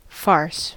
Ääntäminen
IPA: /faʂ/